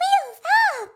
Voice clip from Mario Kart 8